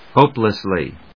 音節hópe・less・ly 発音記号・読み方
/ˈhoplʌsli(米国英語), ˈhəʊplʌsli:(英国英語)/